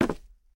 immersive-sounds / sound / footsteps / rails / rails-07.ogg